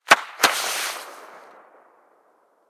Incendiary_Far_05.ogg